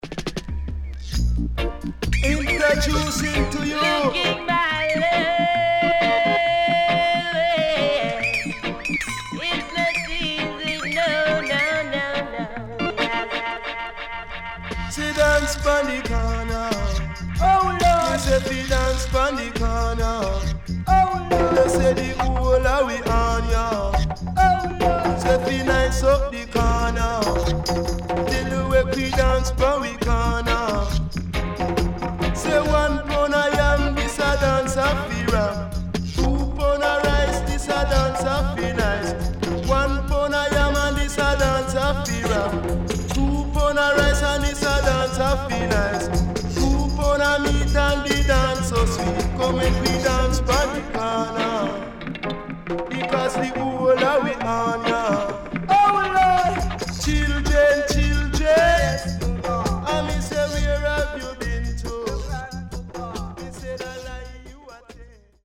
HOME > LP [VINTAGE]  >  70’s DEEJAY
OLD SCHOOL Deejay